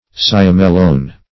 Cyamellone \Cy*am"el*lone\ (s[-i]*[a^]m"[e^]l*l[=o]n)